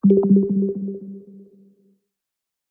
UI_Alert_Obstacle_RedGrid.ogg